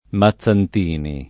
[ ma ZZ ant & ni ]